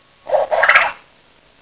Hear the male quail call (wav-file below 50kB).
quailm1.wav